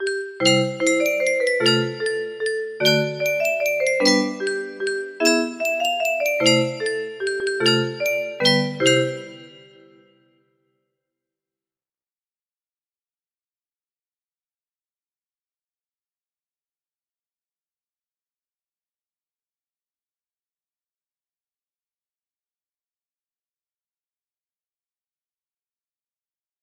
We wish you a merry christmas music box melody